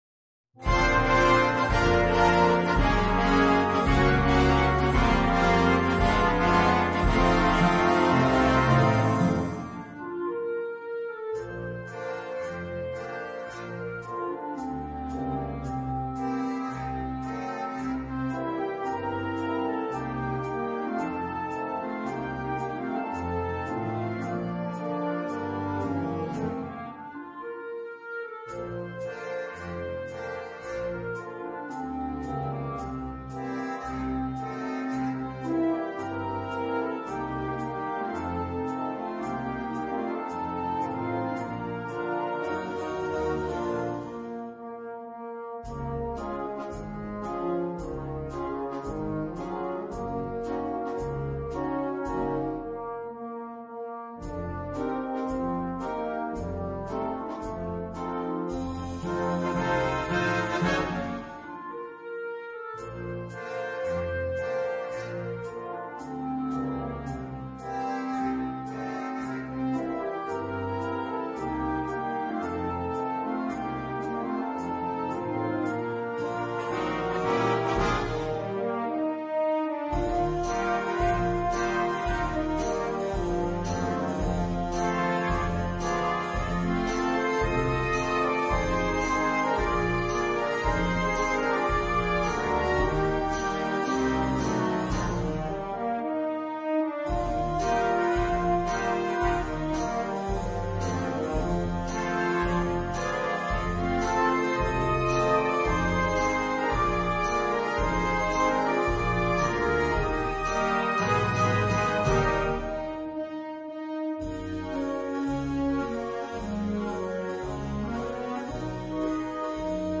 Fantasia di canzoni italiane
caratterizzato da un ritmo leggero e coinvolgente.
una melodia delicata e suggestiva, carica di emozione.